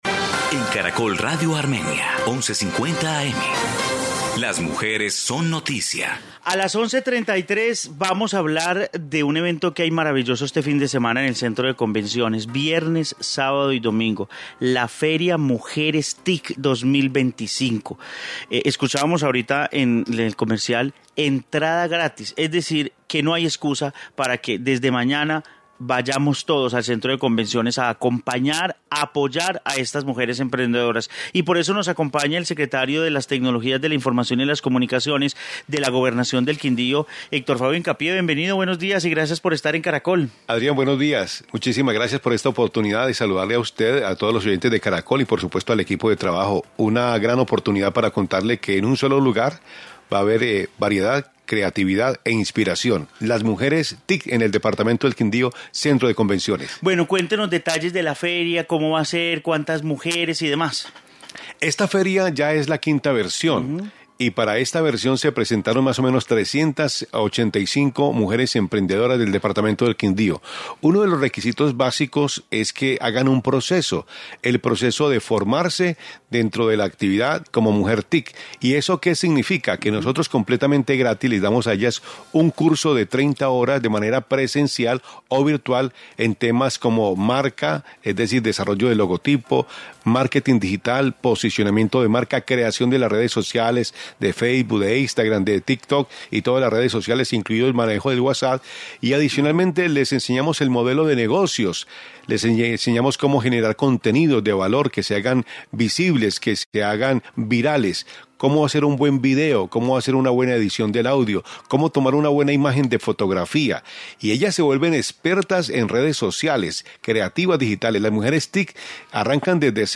Informe Feria Mujeres TIC Quindío 2025